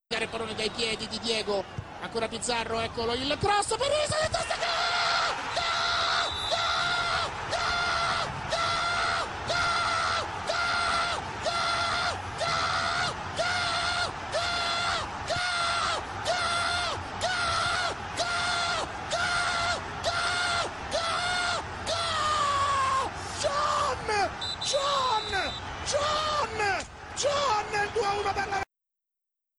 Unten findet Ihr die Reportagen zu acht berühmten Toren, bei denen sich die Kommentatoren besonders ins Zeug gelegt haben, verbunden mit jeweils einer Frage.
Hier müsst Ihr nur zählen: Wie oft brüllt der (etwas peinliche) Kommentator auf Italienisch Tor?